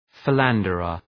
Προφορά
{fı’lændərər}